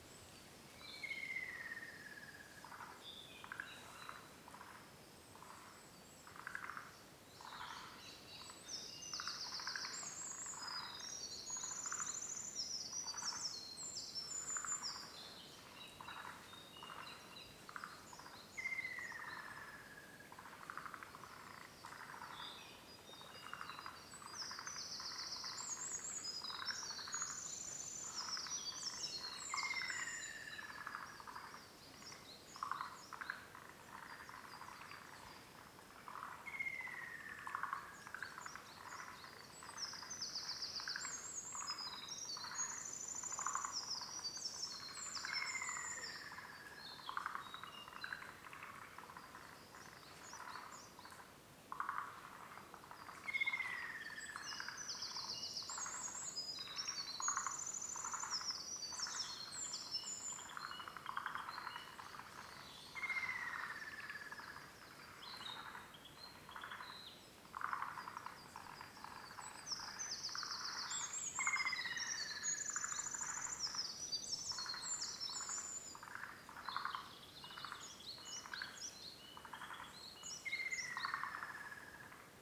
野鳥の鳴き声をアンビソニック収録機材を用いて録音してみる
H3-VRを用いて収録したサンプル音源
収録フォーマット：　[48KHz 24bit 4ch (AmbiX Format) : 1min 22sec]
収録場所：羽黒山神社　鏡池の畔
出演者：　モリアオガエル君、アカショウビン君、ミソサザイ君、他
神社の境内での収録なので少しノイジーだが、アンビソニックサウンドの雰囲気は伝わるのではないかと思う．